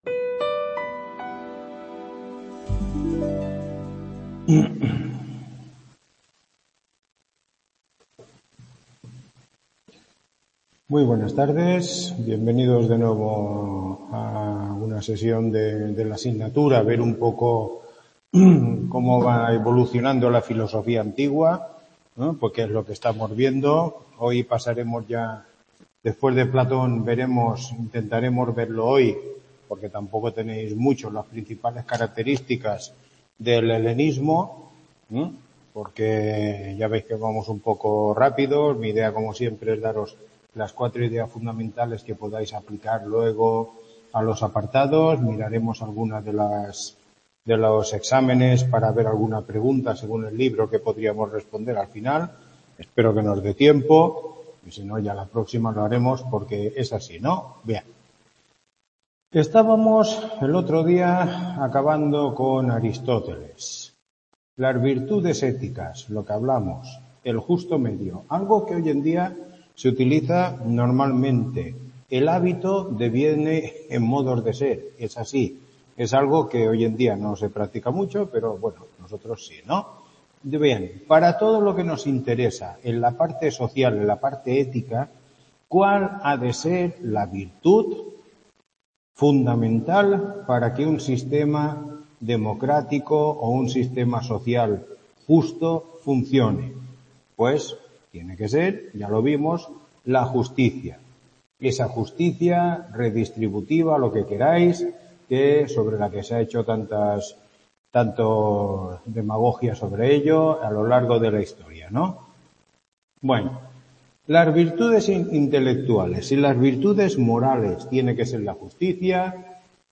Tutoría 06